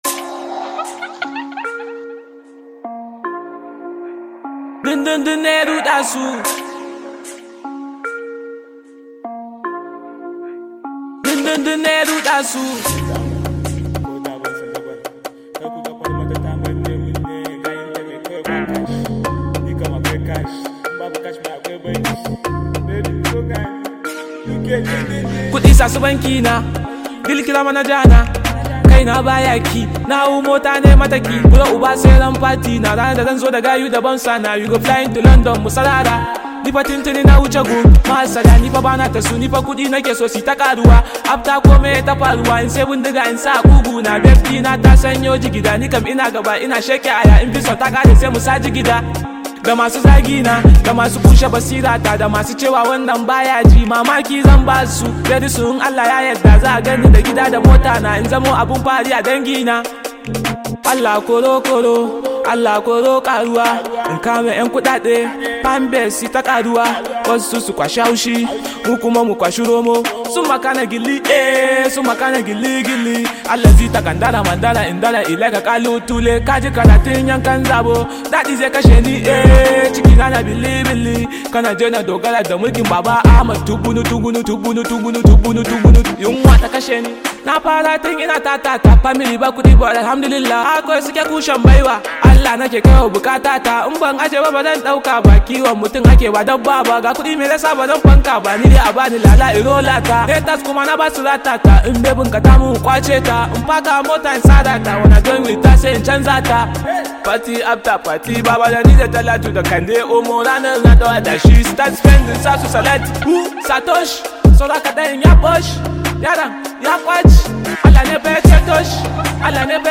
Hausa hiphop song